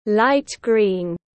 Light green /lait griːn/